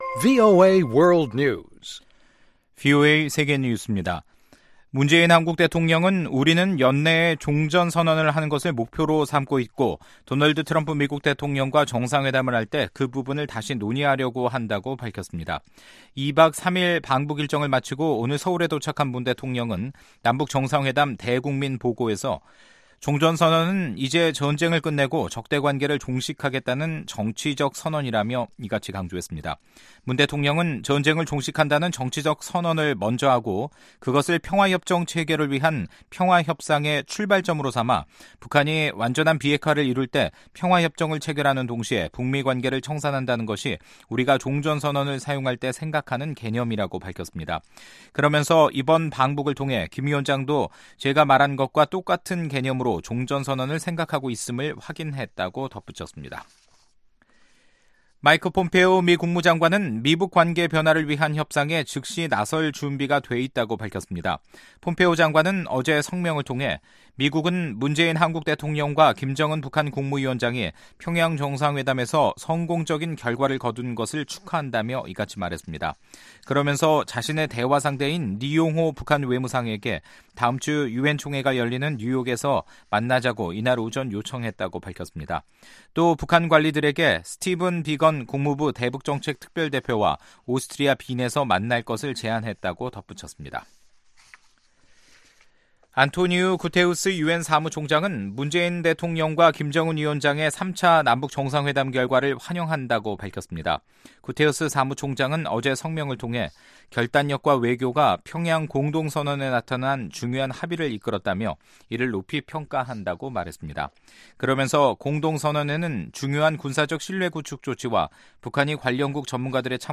VOA 한국어 간판 뉴스 프로그램 '뉴스 투데이', 2018년 9월 20일 2부 방송입니다. 도널드 트럼프 미국 대통령은 남북 정상회담이 엄청난 진전을 이뤄내고 있다고 말했습니다. 한국 외교부는 ‘9월 평양 공동선언’이 미-북 간 대화의 물꼬를 다시 트고 비핵화 의지를 구체화할 수 있는 실천적인 조치에 합의하는 성과가 있었다고 밝혔습니다.